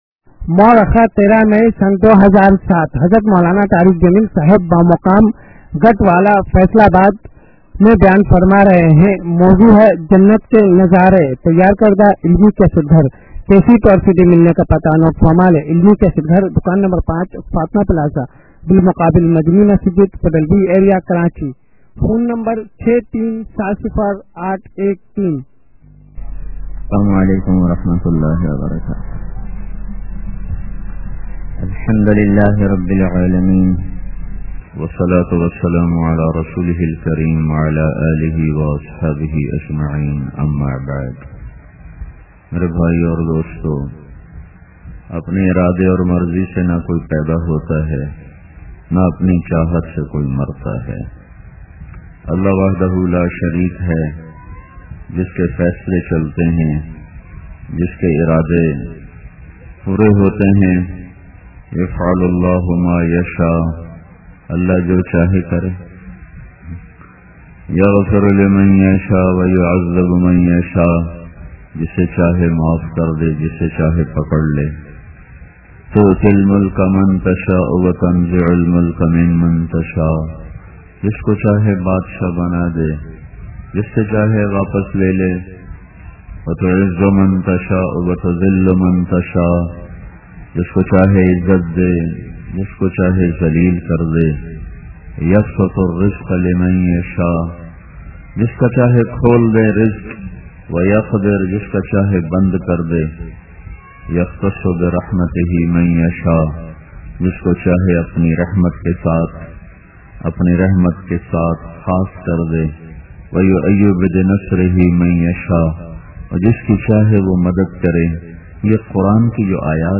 Urdu Bayan Maulana Tariq Jameel is regarded as one of the greatest Islamic preachers of our times.